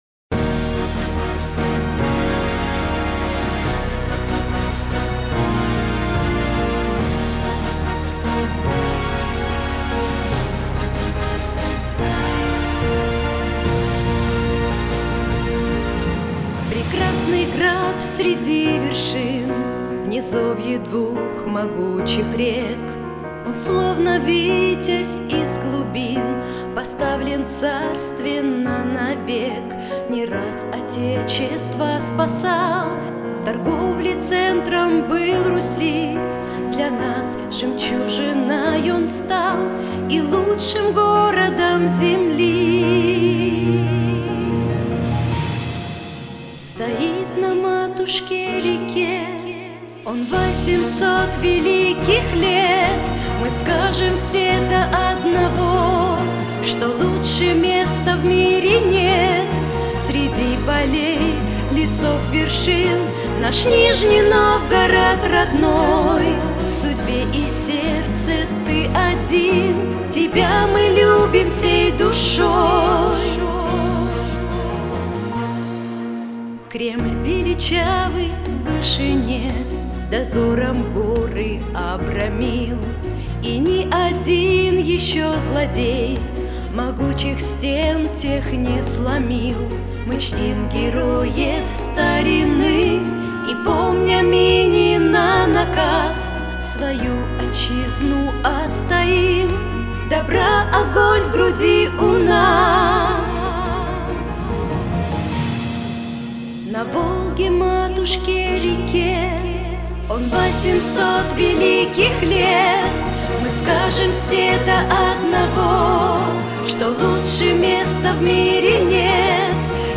песня
164 просмотра 148 прослушиваний 12 скачиваний BPM: 76